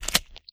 STEPS Pudle, Walk 11.wav